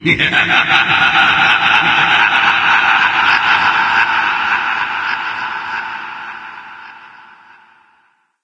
doom_laugh3.mp3